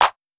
Clp (Storch).wav